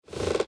archer_skill_towshot_01_load.ogg